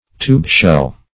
Search Result for " tube-shell" : The Collaborative International Dictionary of English v.0.48: Tube-shell \Tube"-shell`\, n. (Zool.) Any bivalve mollusk which secretes a shelly tube around its siphon, as the watering-shell.